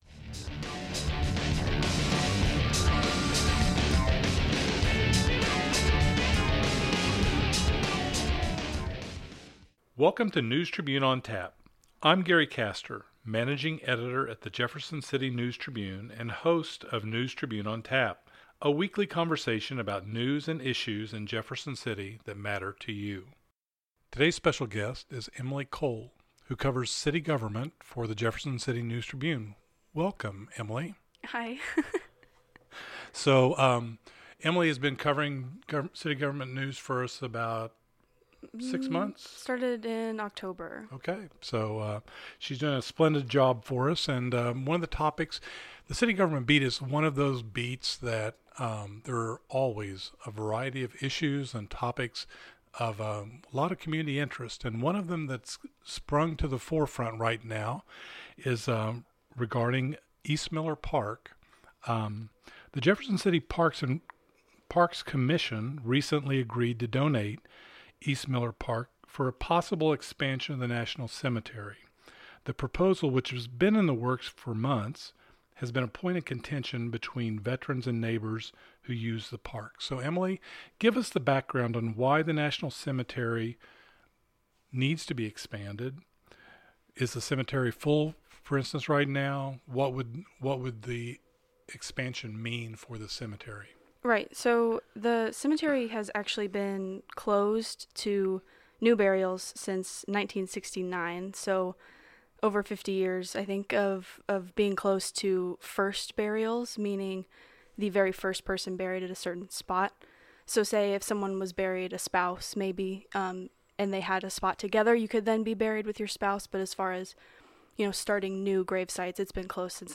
Listen to what both sides of the issue have to say and what the next steps are in the process. Also, don't miss outtakes from the show at the end!